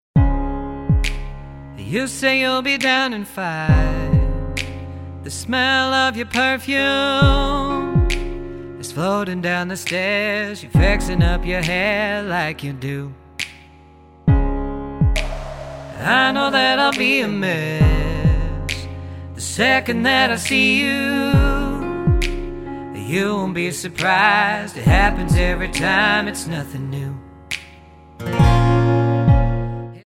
Tonart:C# Multifile (kein Sofortdownload.
Die besten Playbacks Instrumentals und Karaoke Versionen .